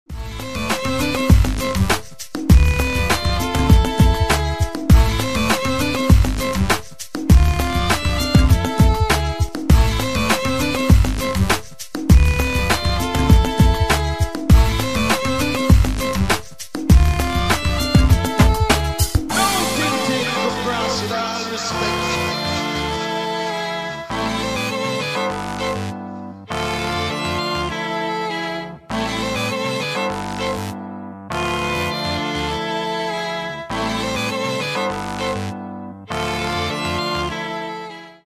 • Качество: 128, Stereo
мужской голос
громкие
dance
электронная музыка
красивая мелодия
Electronica
Downtempo
скрипка